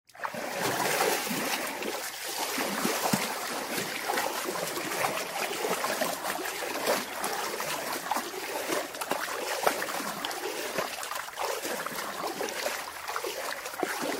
Download Free Swimming Sound Effects
Swimming